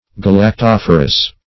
Search Result for " galactophorous" : The Collaborative International Dictionary of English v.0.48: Galactophorous \Gal`ac*toph"o*rous\, a. [Gr.